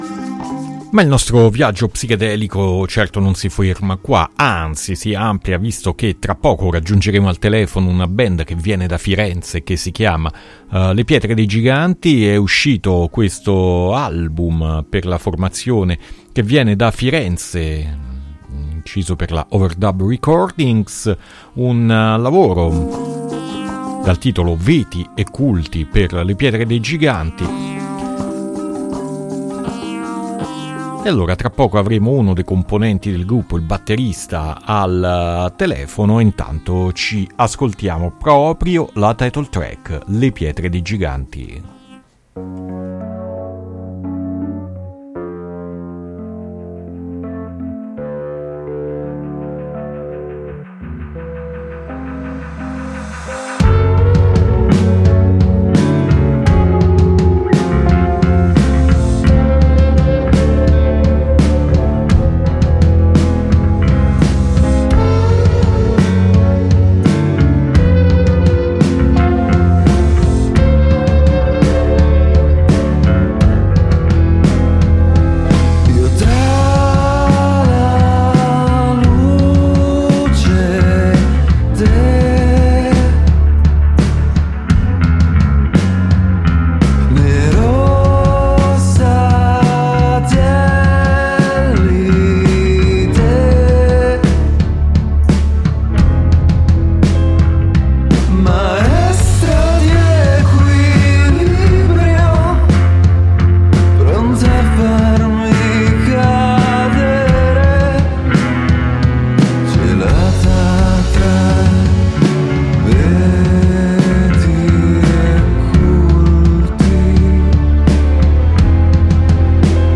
Intervista Le Pietre Dei Giganti ad Alternitalia 1-4-2022